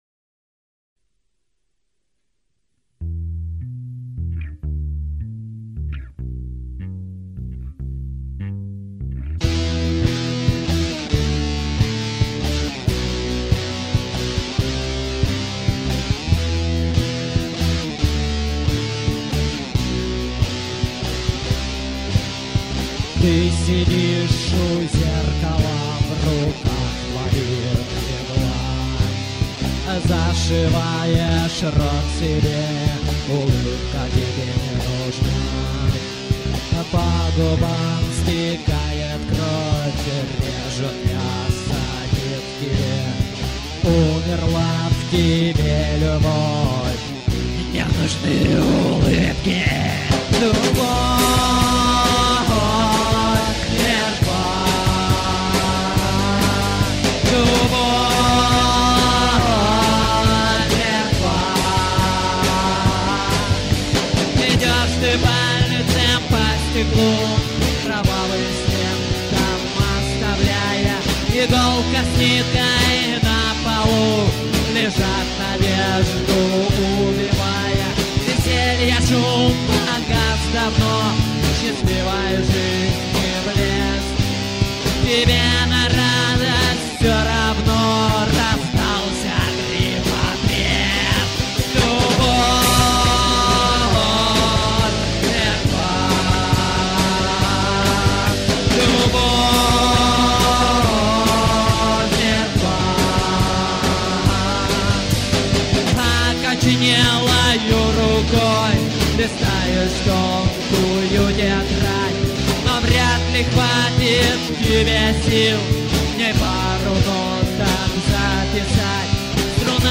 1-6: Записано на студии "Рай" весной 2004г.